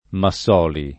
[ ma SS0 li ]